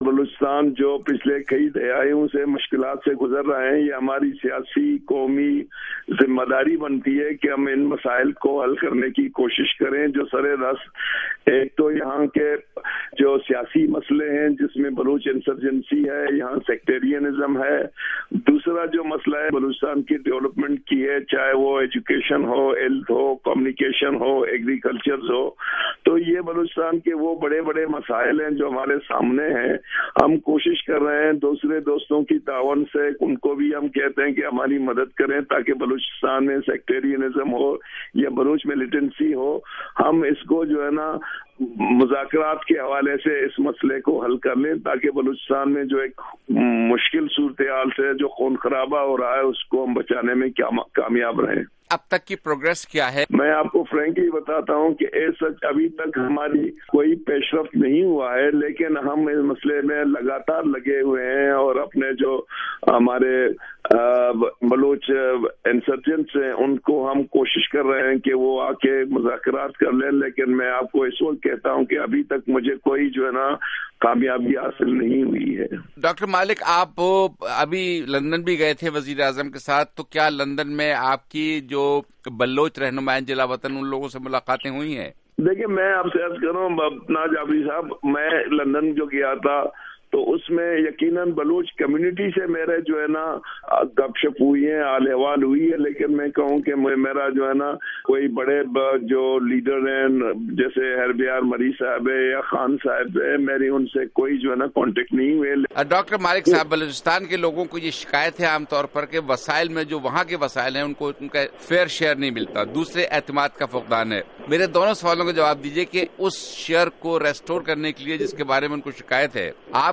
بلوچستان کے وزیر اعلیٰ، ڈاکٹر عبد المالک سے بات چیت
Talk with Balochistan Chief Minister, Dr. Abdul Malik